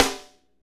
SNR FNK S06L.wav